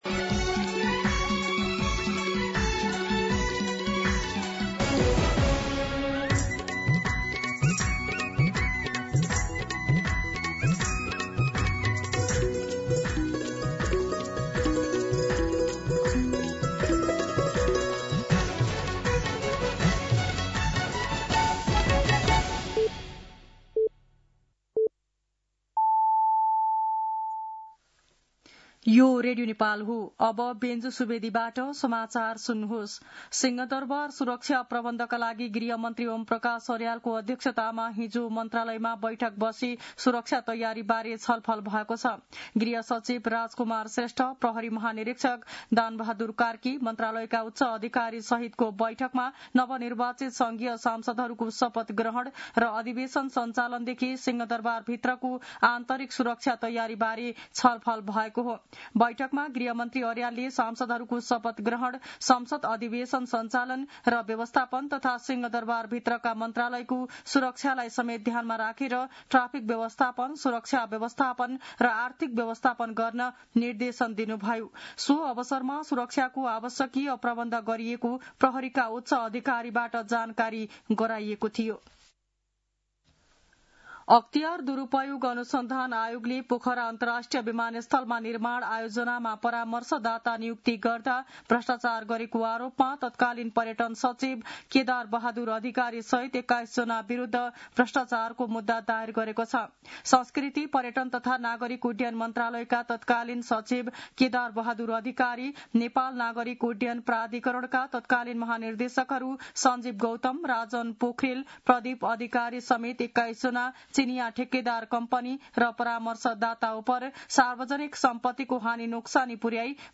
मध्यान्ह १२ बजेको नेपाली समाचार : ९ चैत , २०८२